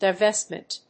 音節di・vést・ment 発音記号・読み方
/‐mənt(米国英語), daɪˈvestmʌnt(英国英語)/